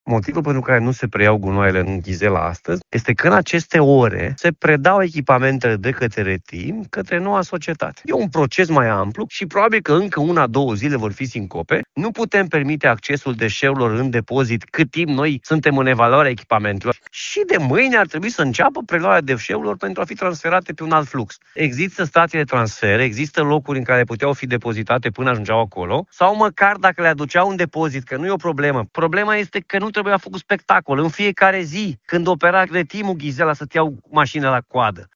Președintele Consiliului Județean Timiș, Alfred Simonis, susține că operațiunea nu se poate efectua până la recepționarea echipamentelor de lucru.